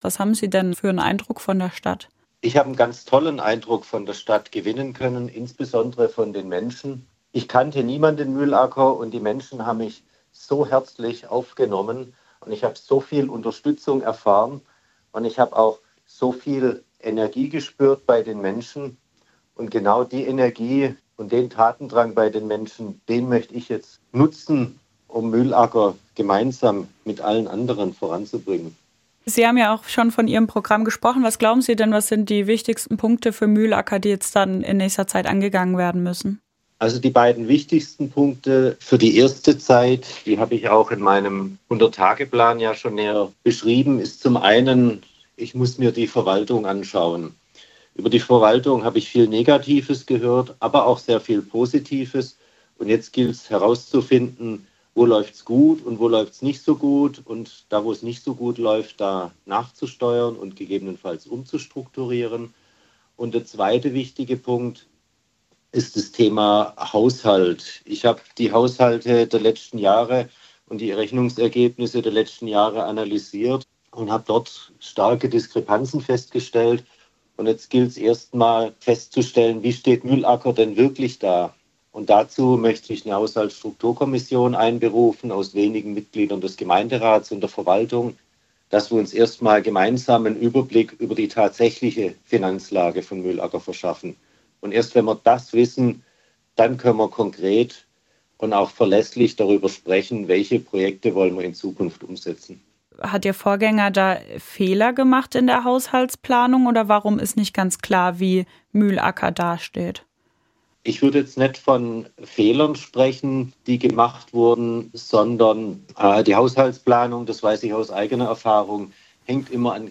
Stephan Retter im SWR-Interview: